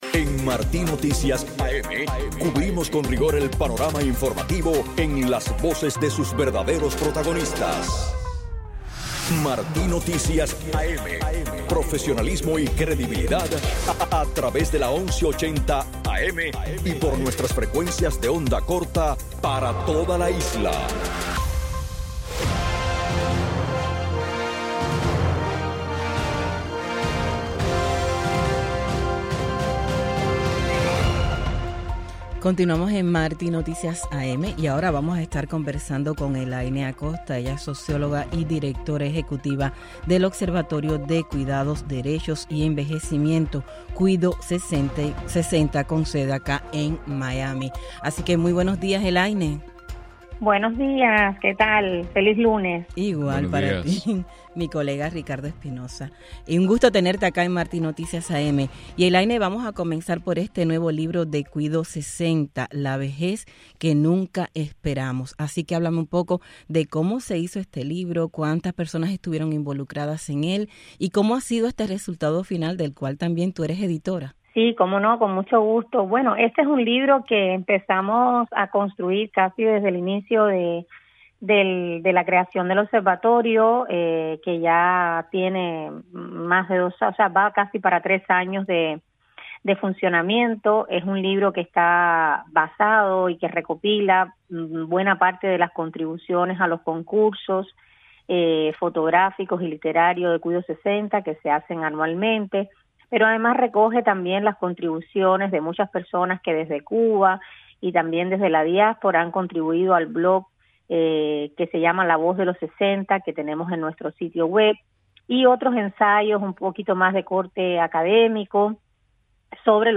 Entrevista en la revista informativa Martí Noticias AM